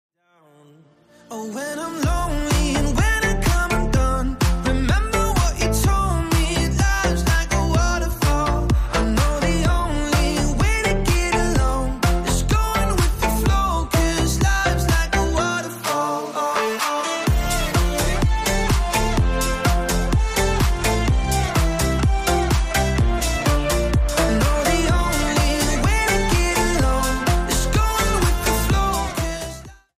Dance / Electronica